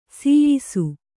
♪ sīyisu